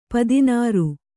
♪ padināru